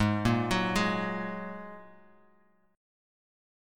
G#7sus2 chord